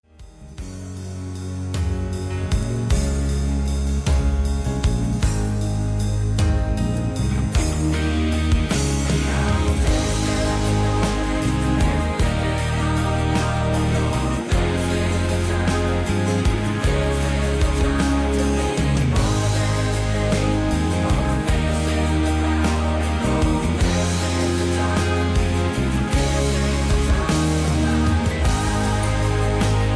Key-Ab